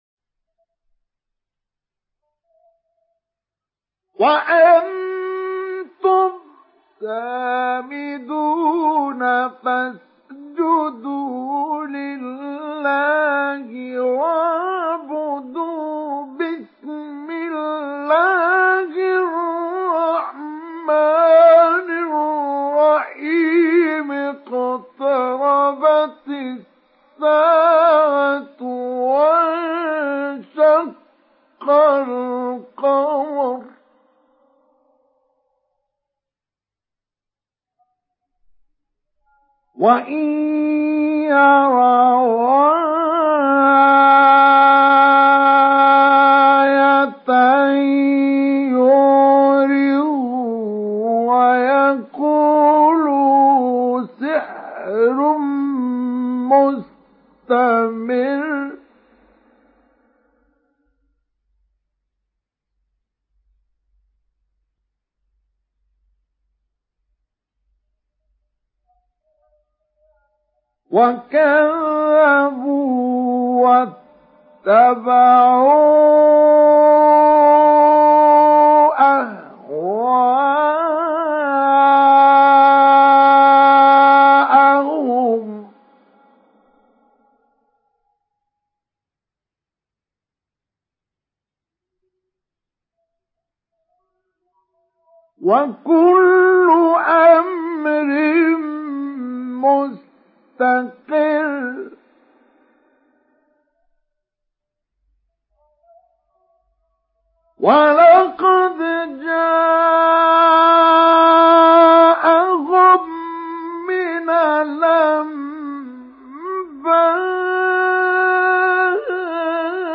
Surah আল-ক্বামার MP3 in the Voice of Mustafa Ismail Mujawwad in Hafs Narration
Listen and download the full recitation in MP3 format via direct and fast links in multiple qualities to your mobile phone.